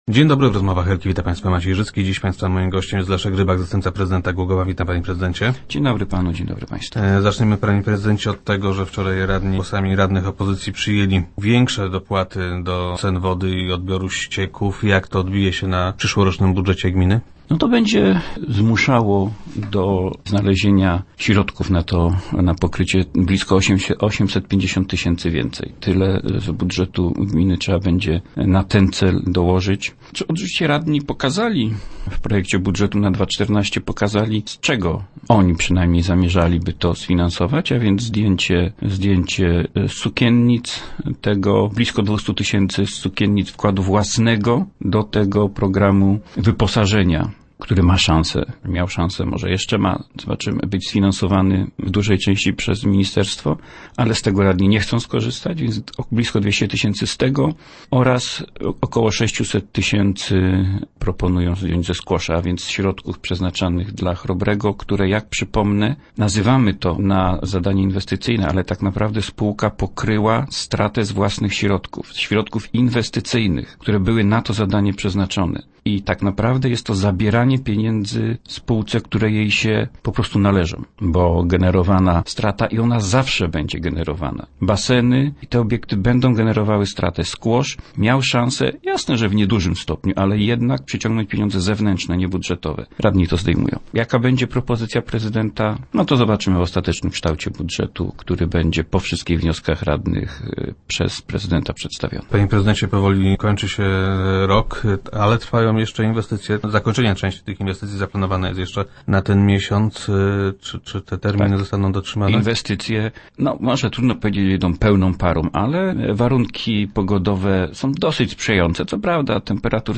0101_rybak_do_rozmow.jpgRada miasta przyjęła uchwałę zwiększającą dopłaty do wody i ścieków. Jak twierdzi Leszek Rybak, zastępca prezydenta, nie najlepiej to wpłynie na przyszłoroczny budżet.